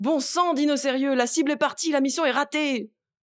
VO_ALL_EVENT_Temps ecoule_02.ogg